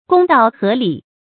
公道合理 注音： ㄍㄨㄙ ㄉㄠˋ ㄏㄜˊ ㄌㄧˇ 讀音讀法： 意思解釋： 見「公平合理」。